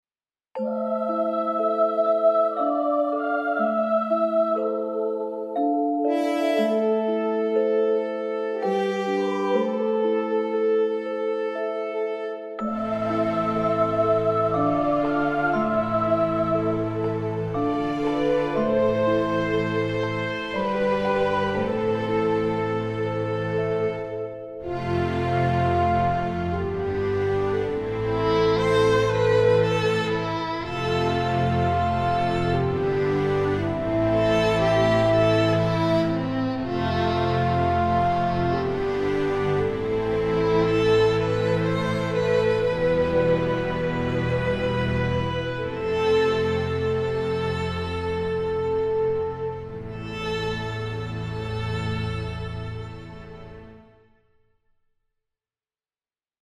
Orchestration